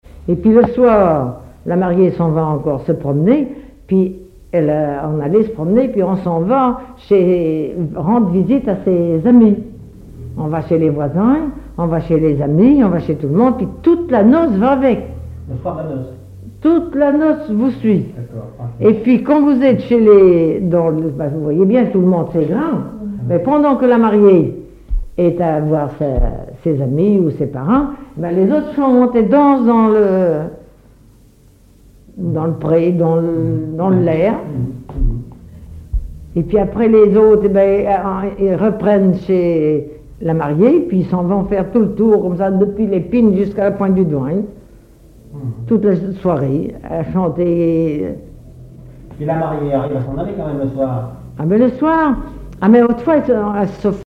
Témoignages et chansons traditionnelles
Catégorie Témoignage